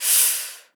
Tm8_Chant34.wav